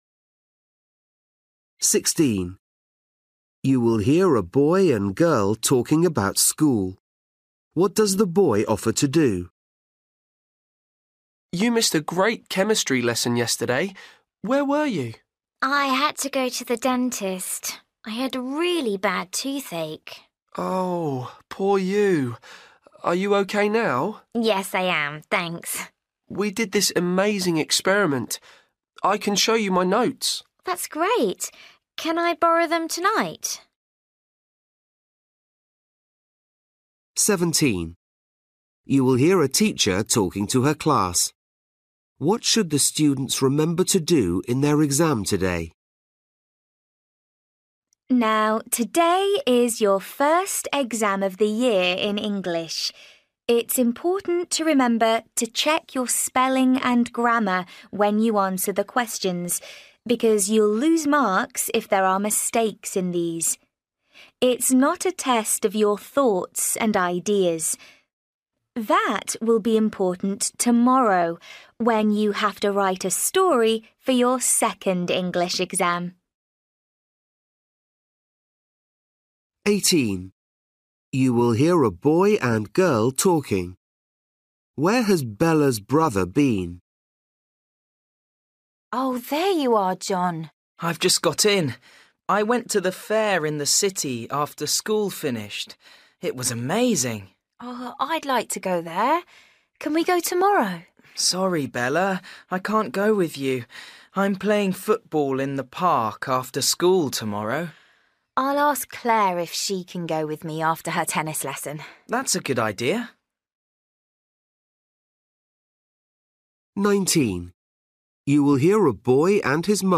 Listening: everyday short conversations
16   You will hear a boy and girl talking about school.
17   You will hear a teacher talking to her class.
20   You will hear a girl talking to her father.